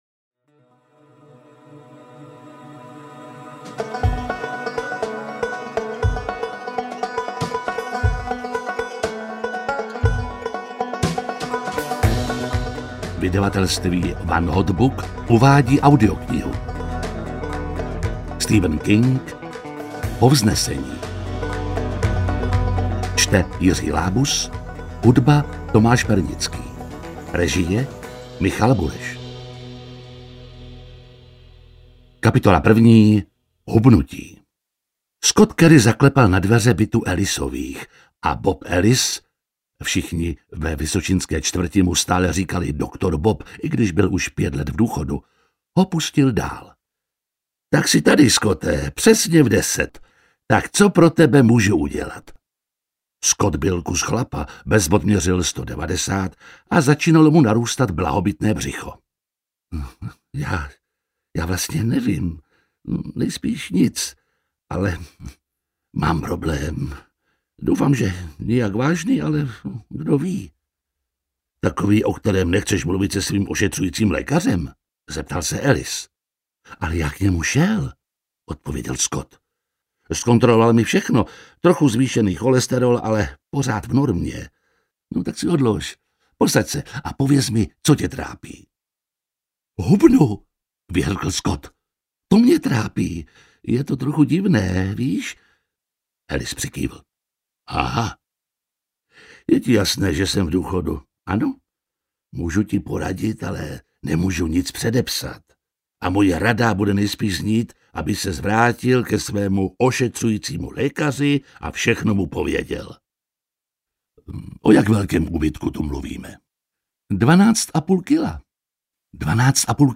Povznesení audiokniha
Ukázka z knihy
• InterpretJiří Lábus